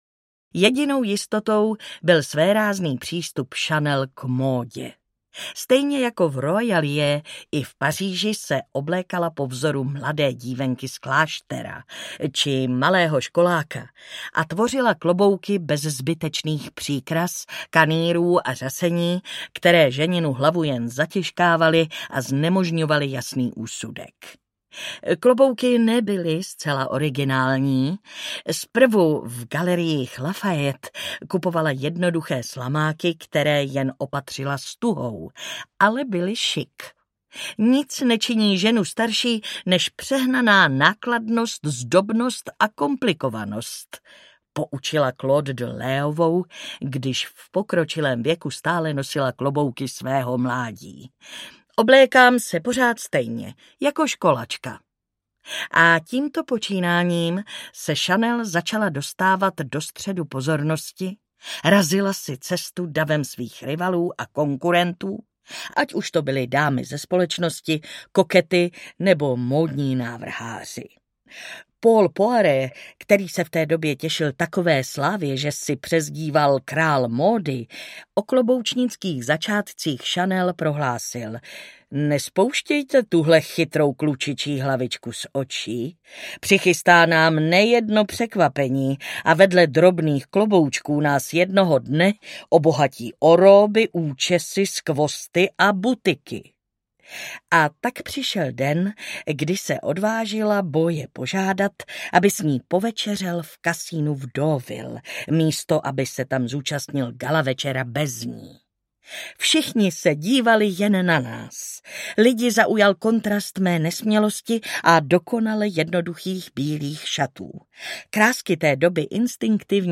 Coco Chanel: Legenda a skutečnost audiokniha
Ukázka z knihy
Čte Martina Hudečková.
Vyrobilo studio Soundguru.